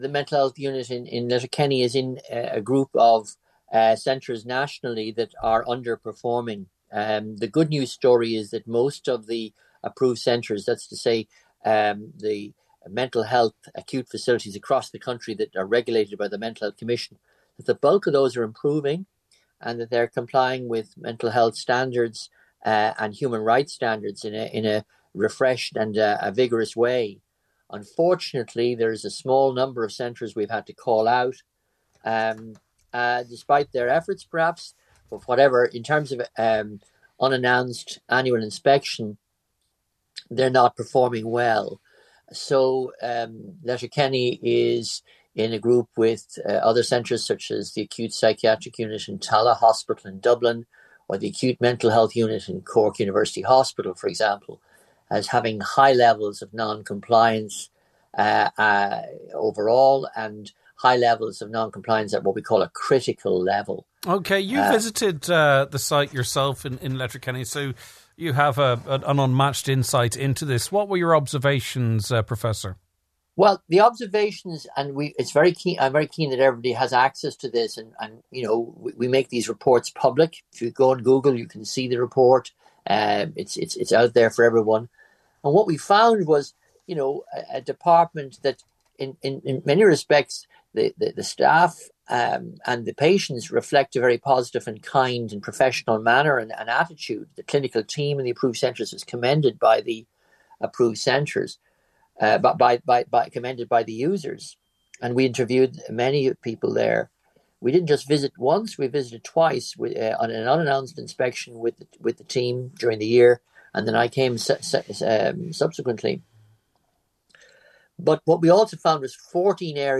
Professor Jim Lucey is the Inspector of Mental Health Services at the Mental Health Commission of Ireland.